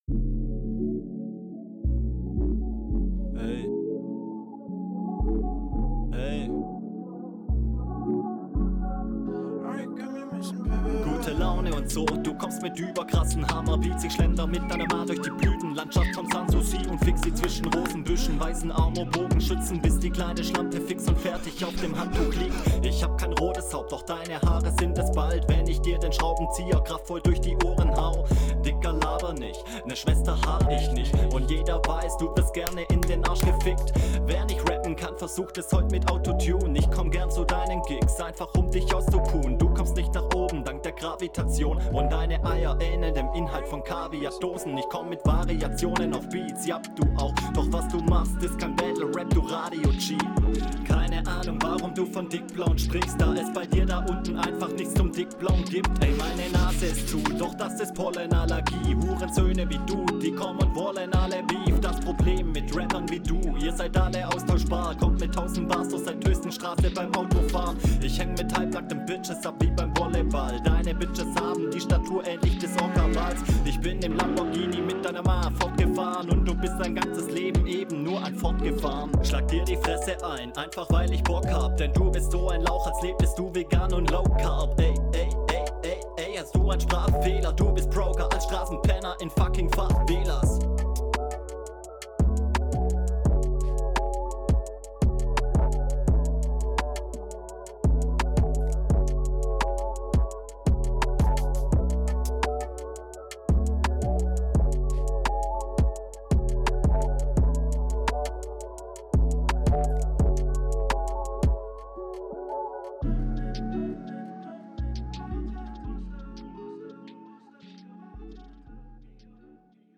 sehr monoton alles.